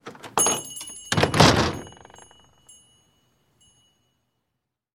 Звуки дверных колокольчиков